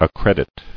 [ac·cred·it]